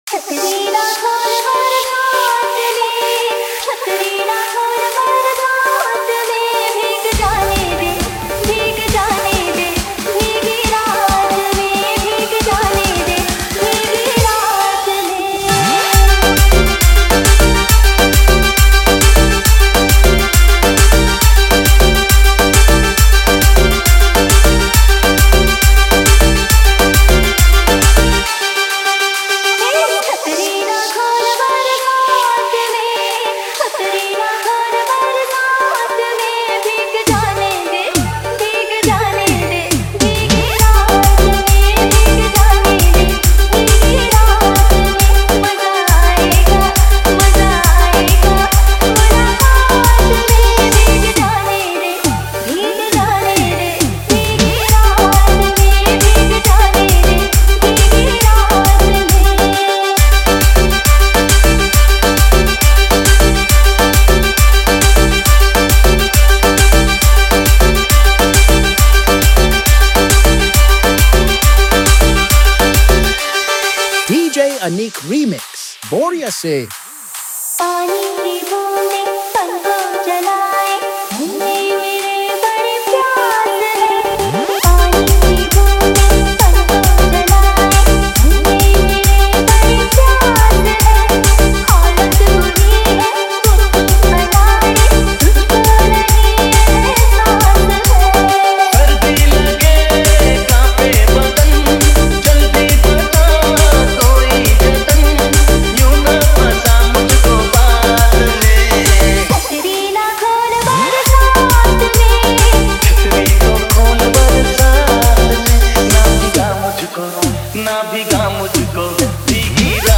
Category : Hindi Remix Song